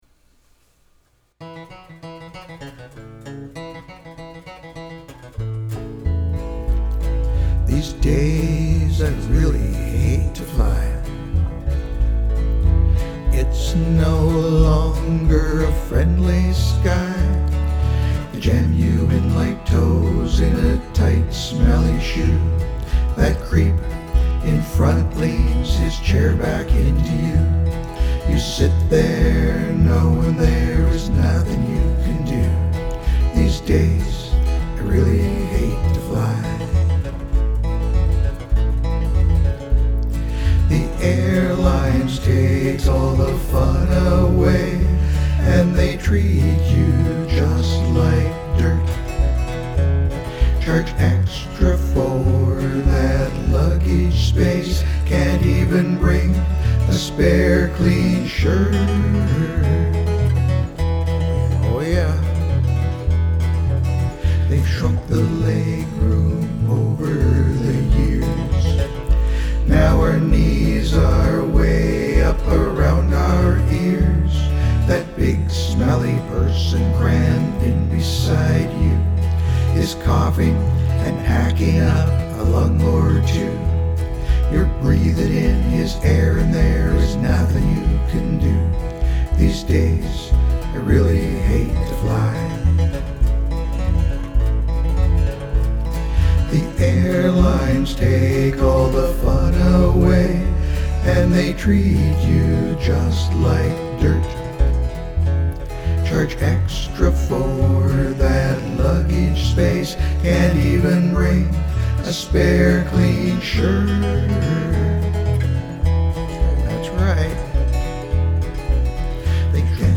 All vocals and instrumentation is by me.